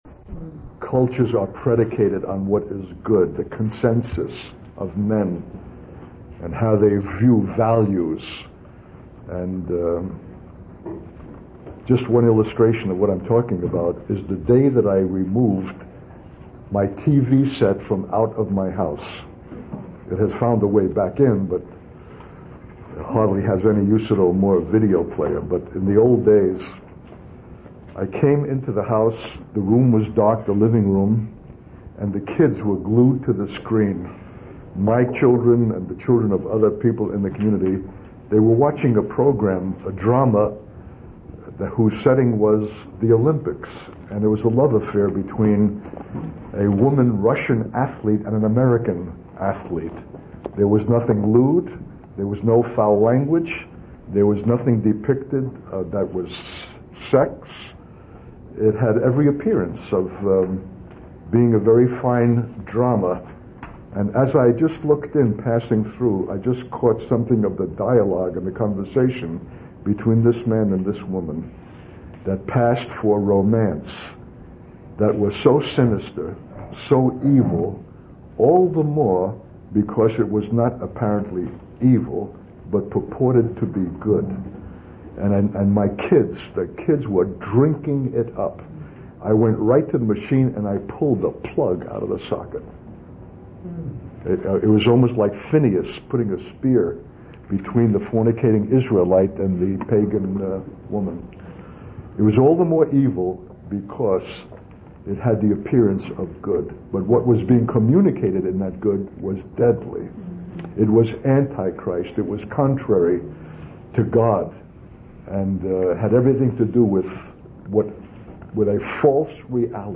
In this sermon, the speaker shares a personal experience of engaging in conversations with students at a university. The speaker emphasizes the importance of engaging in discussions about ultimate questions and values. The sermon also touches on the influence of media and the need to be discerning about what we consume.